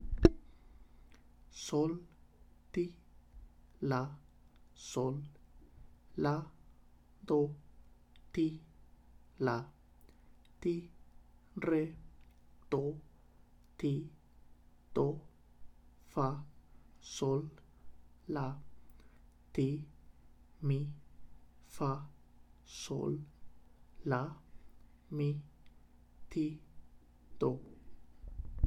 Exercise 5 – Spoken